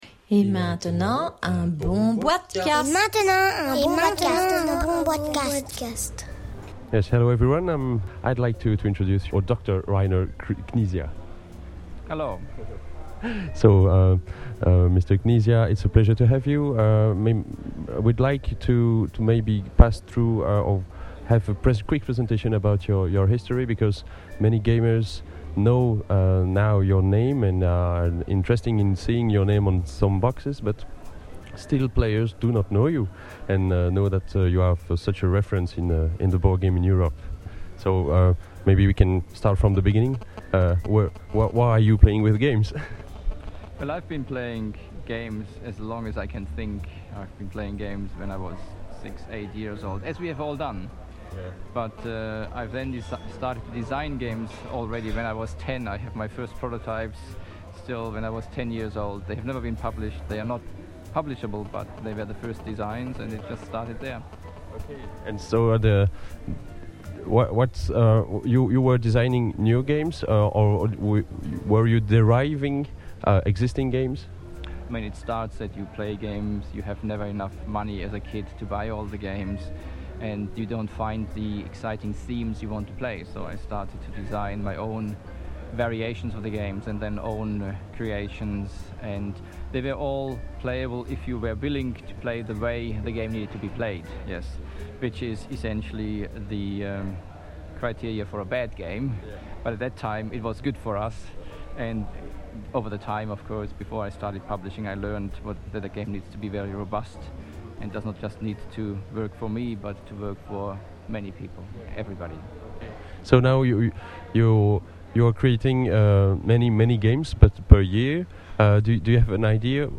Une bon boitecast enregistré entre 2 rendez-vous dans le planning très chargé comme on peut l'iimaginer.
L'interview est en anglais et vous trouverez la traduction dans la suite de l'article.
Nous sommes ici à la foire du jouet de Nuremberg et les jeux publiés ici furent finalisé il y a un an et j'ai travaillé à leur dévelopement pendant  6 mois pour certains et 2 ans pour d'autres.